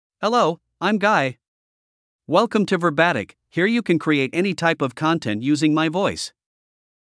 MaleEnglish (United States)
GuyMale English AI voice
Guy is a male AI voice for English (United States).
Voice sample
Listen to Guy's male English voice.
Guy delivers clear pronunciation with authentic United States English intonation, making your content sound professionally produced.